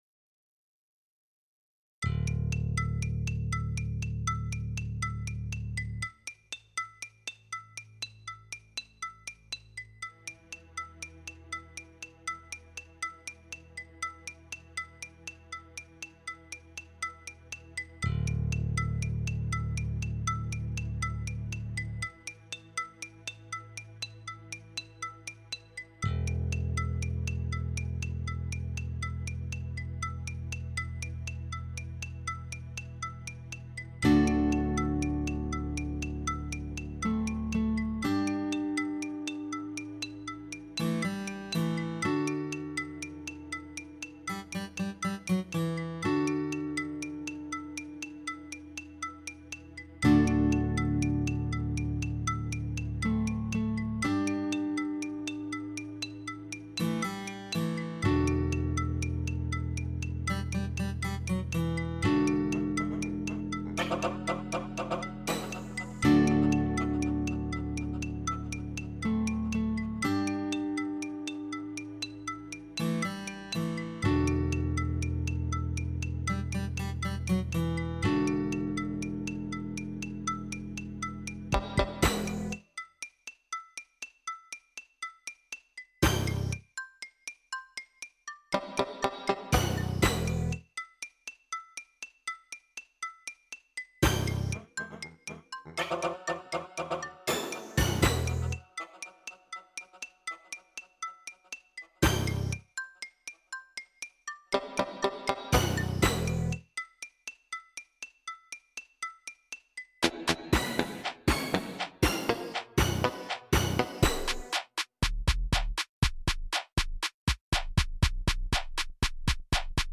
Darin sind mehrere Instrumente und Stimmen enthalten.